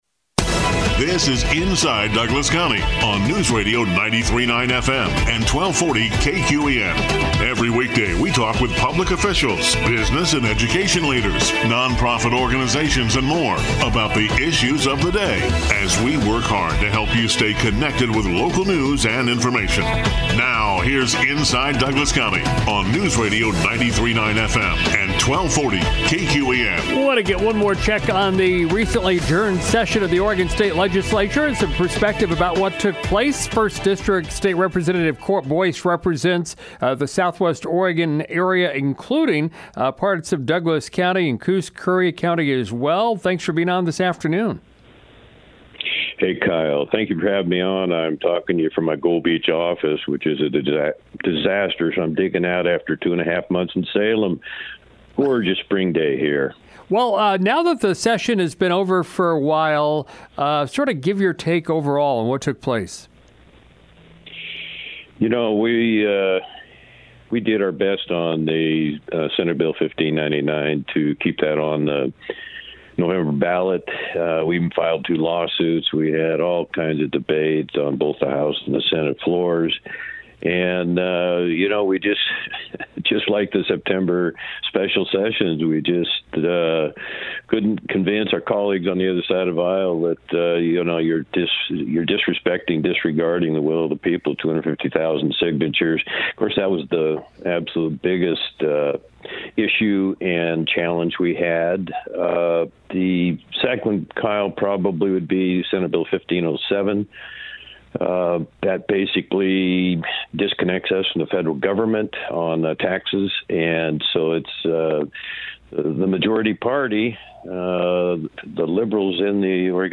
Live from Gold Beach, First-District State Representative Court Boice looks back at the short session of the legislature and talks about his future plans.